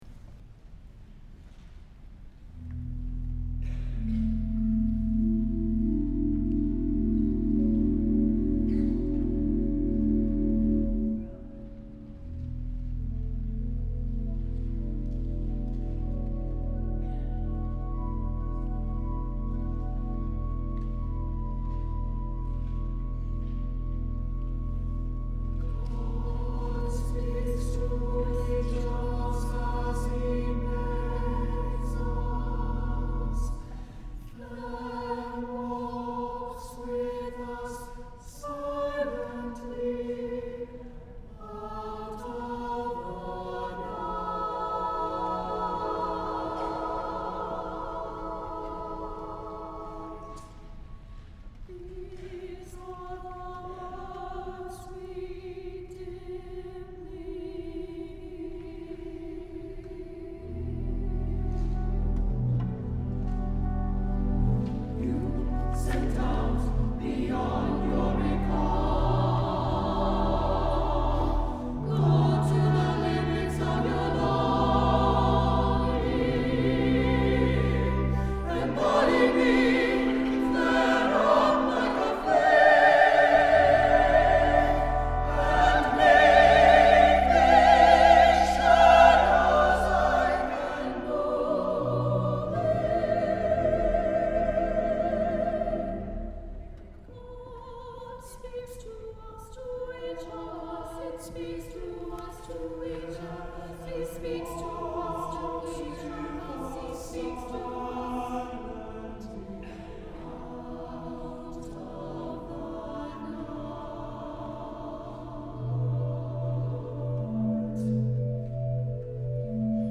for SATB Chorus and Organ (1999)